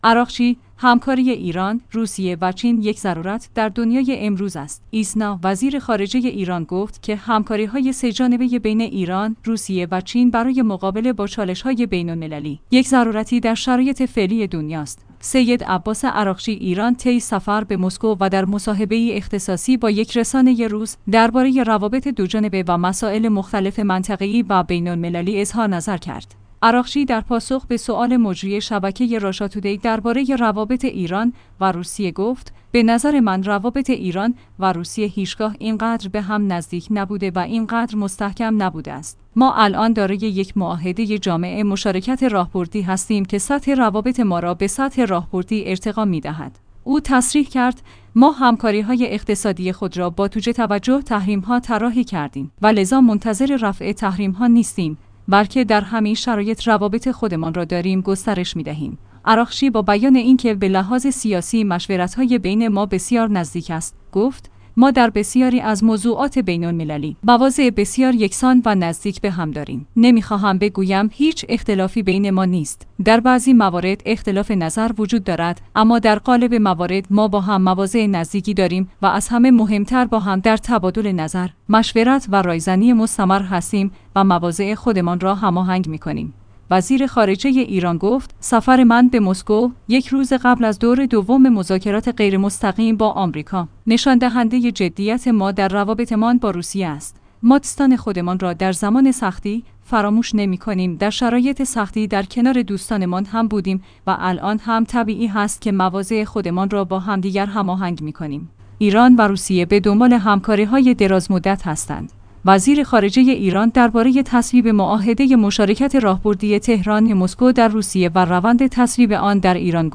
مصاحبه اختصاصی عراقچی با راشاتودی؛ از روابط دوجانبه ایران با روسیه تا بحث وضعیت منطقه و عدم ارتباط با دولت جدید سوریه
ایسنا/ «سید عباس عراقچی» وزیر خارجه ایران طی سفر اخیر به مسکو و در مصاحبه‌ای اختصاصی با یک رسانه روس، درباره روابط دوجانبه و مسائل مختلف منطقه‌ای و بین‌المللی من جمله وضعیت قفقاز و موضوع عدم ارتباط با دولت جدید سوریه اظهارنظر کرد.